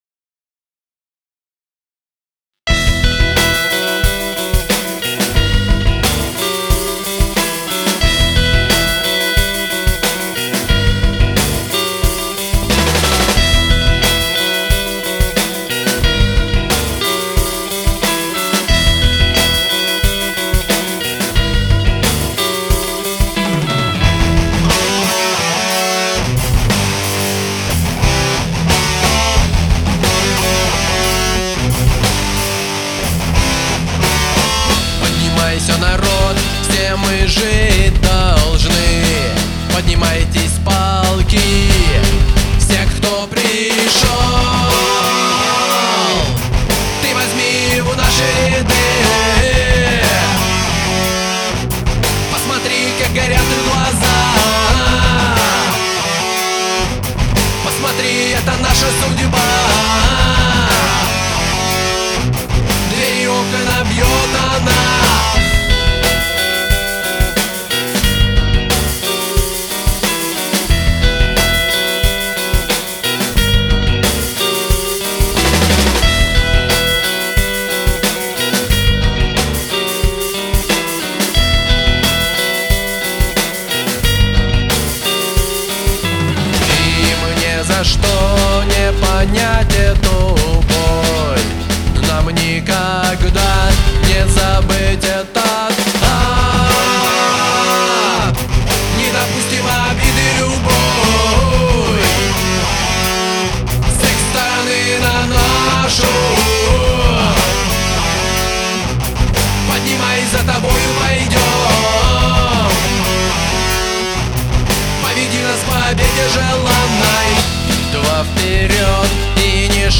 • Жанр: Металл